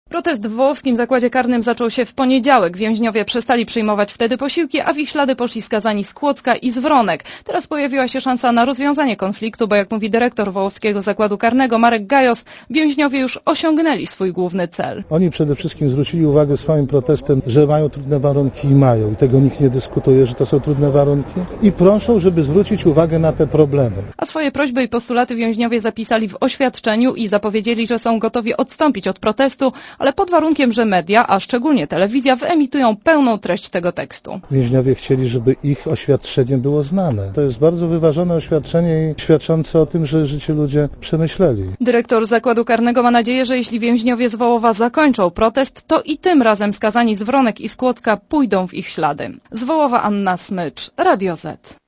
Posłuchaj relacji reporterki Radia ZET (201 KB)